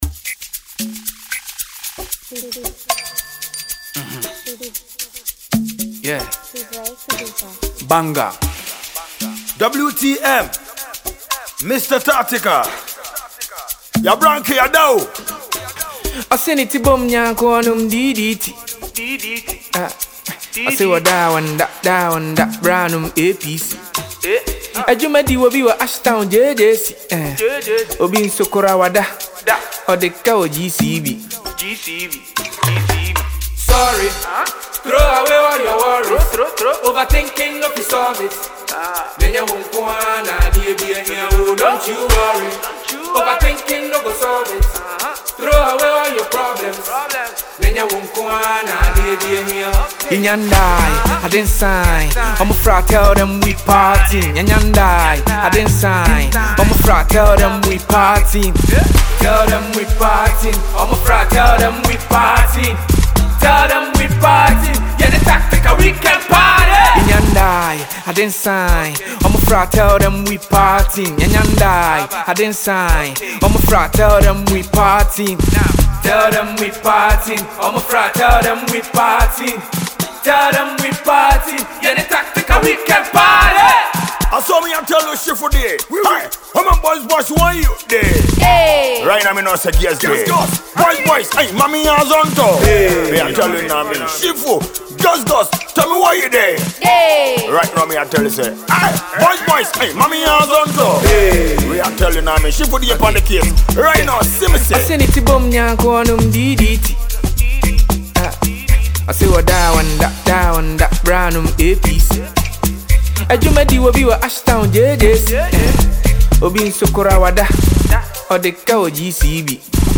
It is a catchy and lively melody.
Highlife and Afrobeat